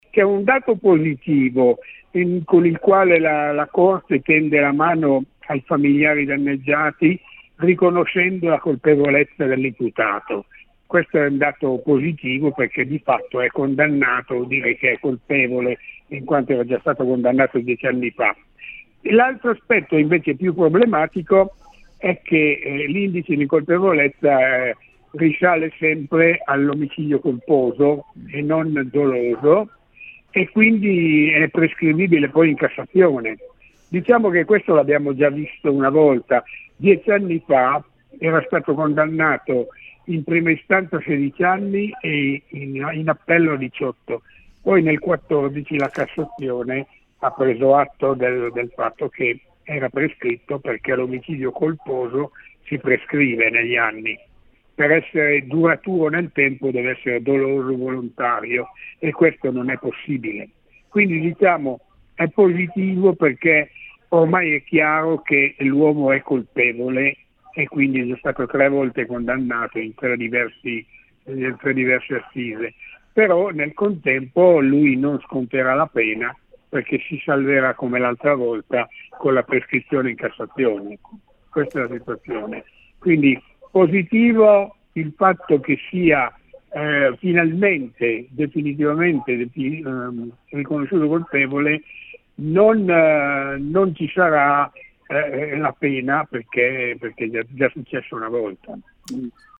Di seguito l’intervista